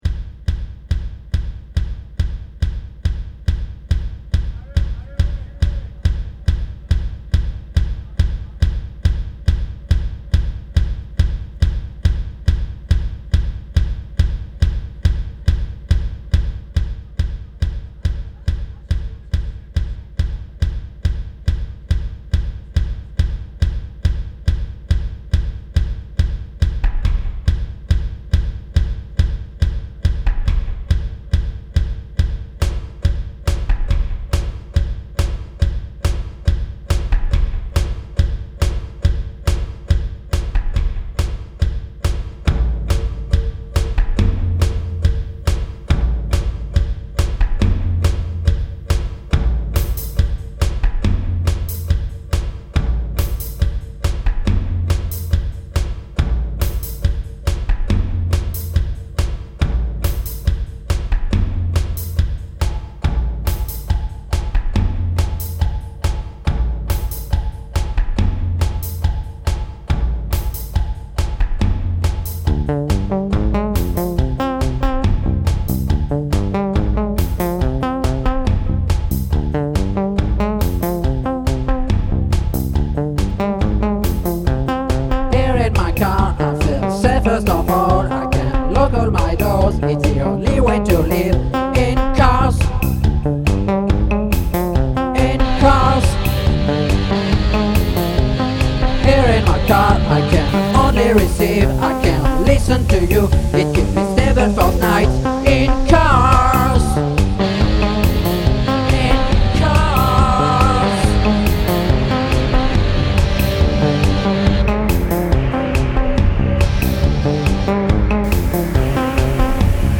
LIVE IN BLOCKAUS DY10